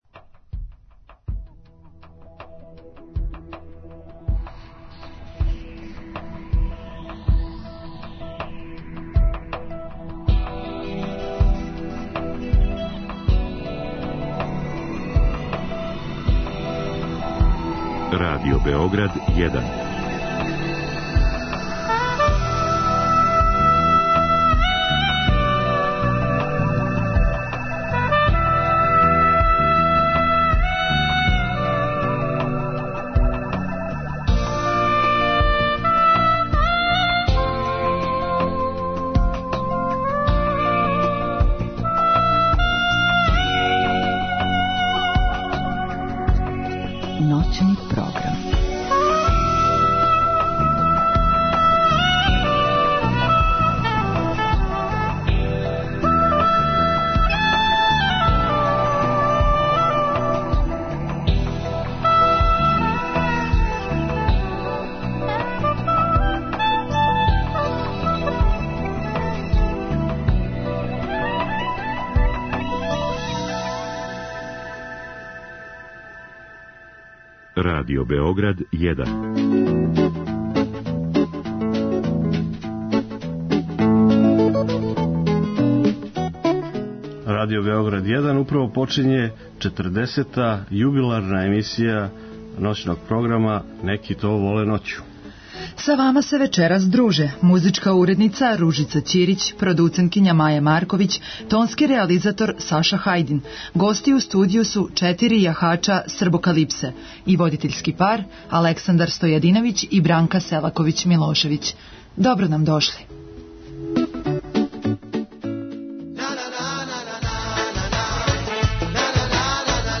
Ове недеље ћемо их угостити у емисији и упитати за најновије информације из света србокалиптичара, промоцијама које следе, али подсетиће нас и на дан њиховог упознавања.